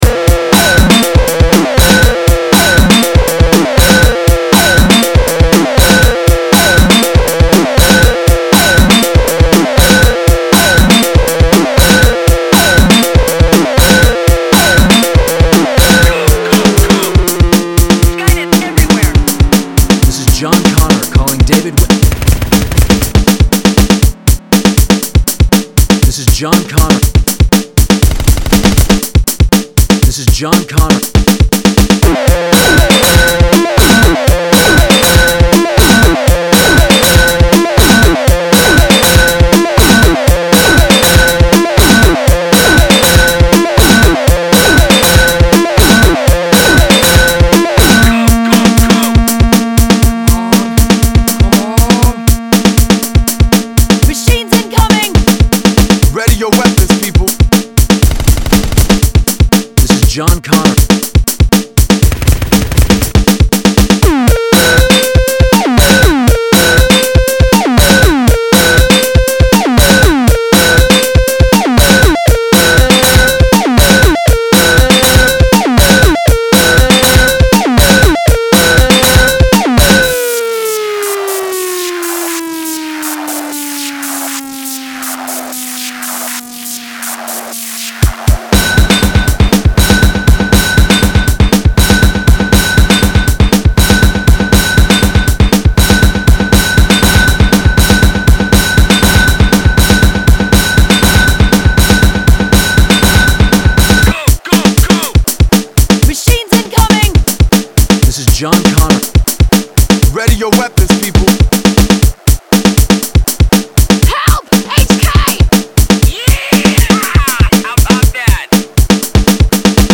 Electronic Experimental